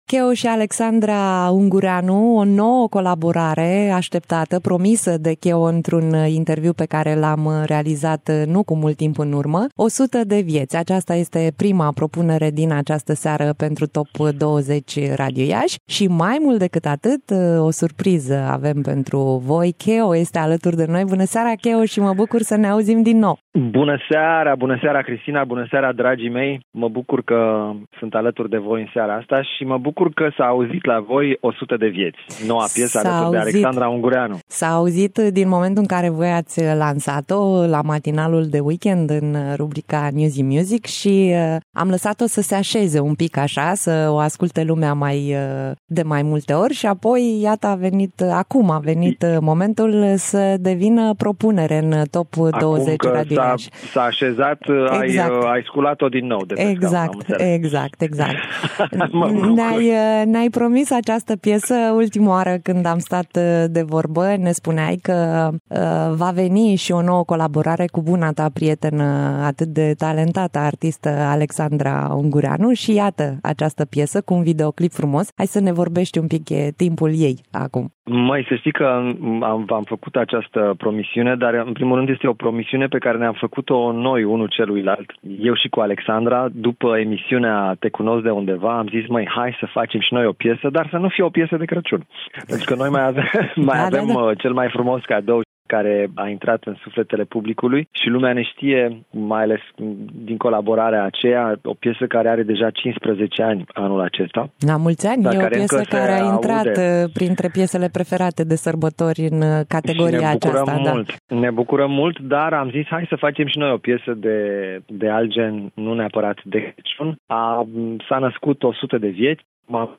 (INTERVIU) Keo
Interviu-KEO.mp3